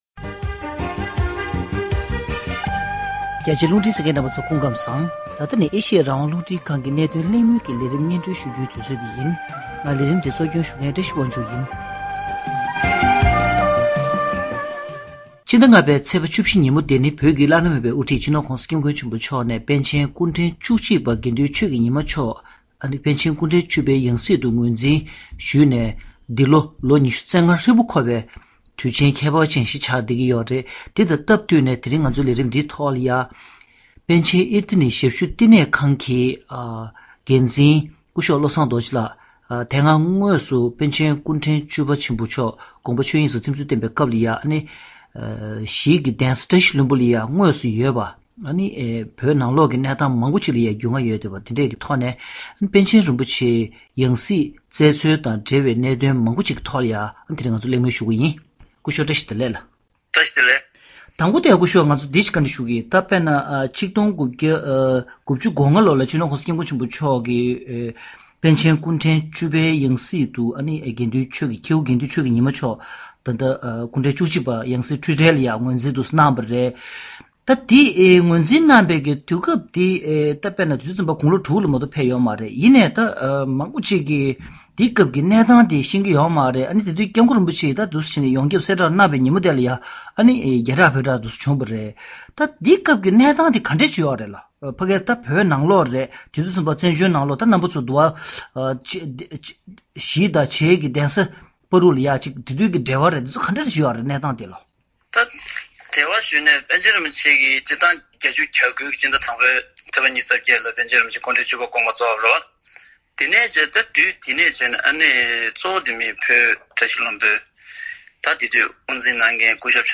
༄༅༎གནད་དོན་གླེང་མོལ་གྱི་ལས་རིམ་ནང་།